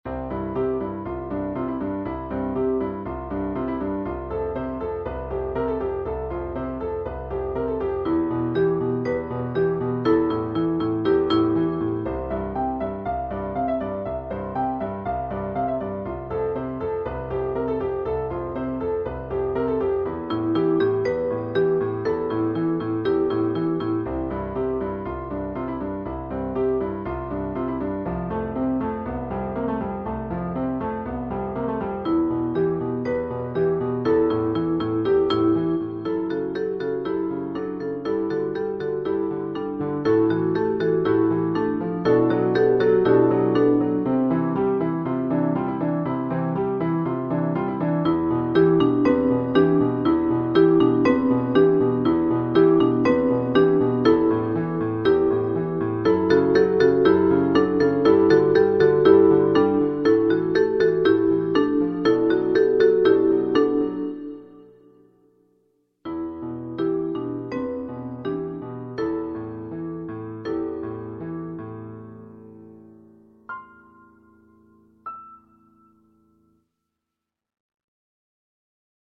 7 haiku per voci e pianoforte